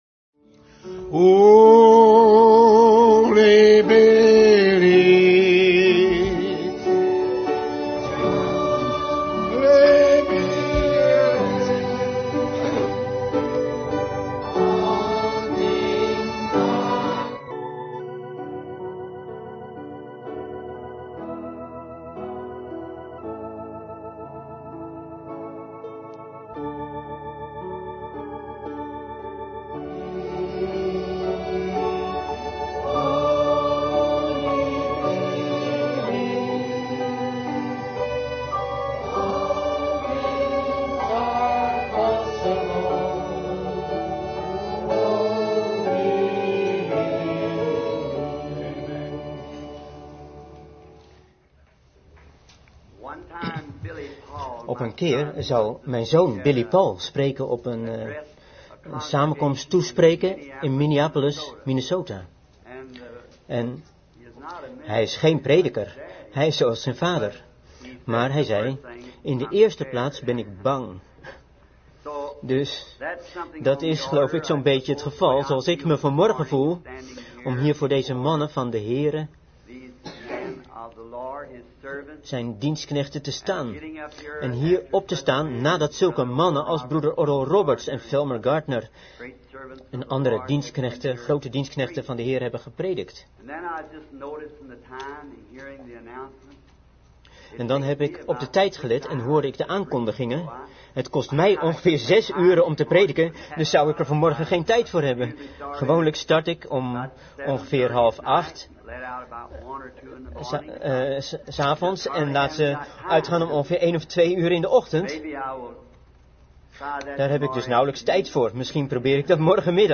Vertaalde prediking "The meanest man I know" door William Marrion Branham te Ramada inn, Phoenix, Arizona, USA, 's ochtends op zaterdag 27 januari 1962